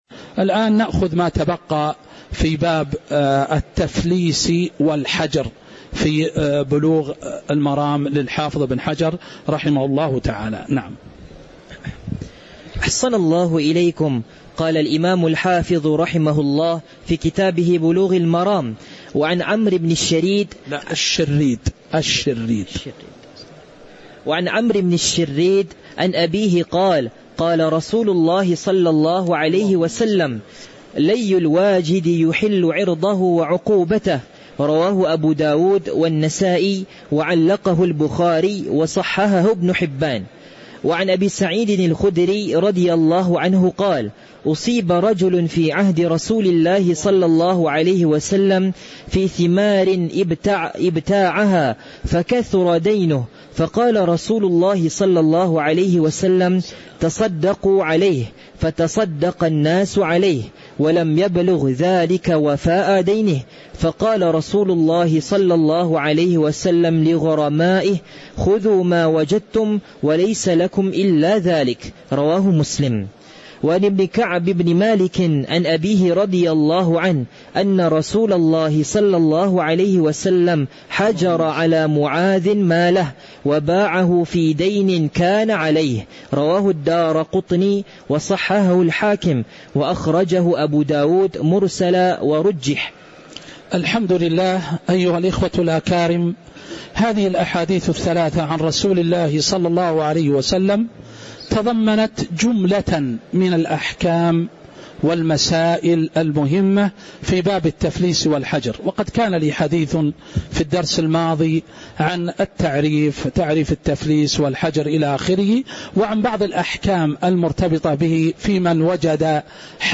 تاريخ النشر ١٤ جمادى الأولى ١٤٤٦ هـ المكان: المسجد النبوي الشيخ